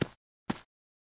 AV_footstep_walkloop.ogg